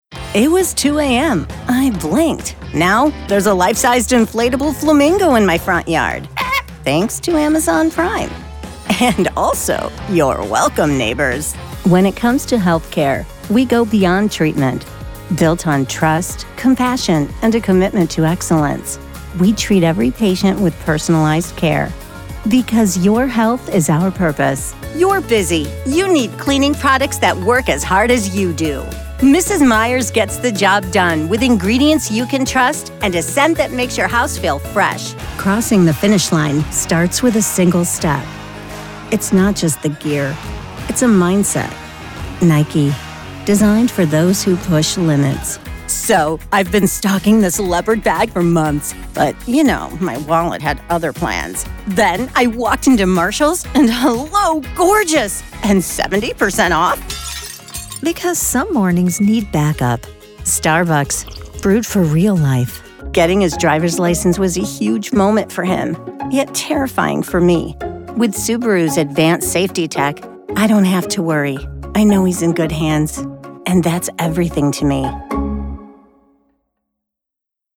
Voice Age
Young Adult
Middle Aged